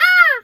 bird_peacock_scream_02.wav